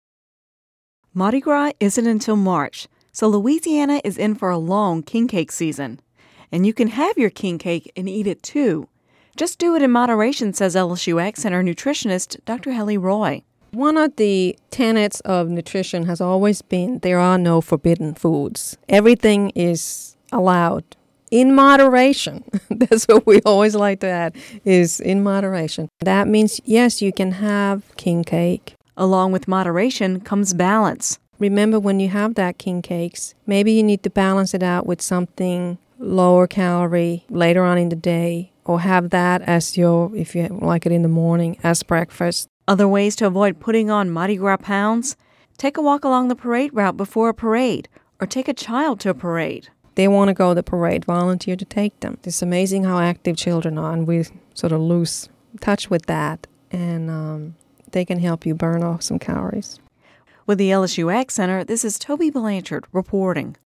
(Radio News 01/24/11) Mardi Gras isn’t until March, so Louisiana is in for a long king cake season.